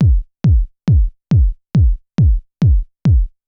BD        -R.wav